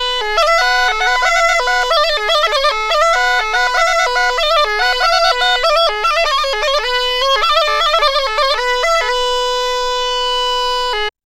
AFGANPIPE1-L.wav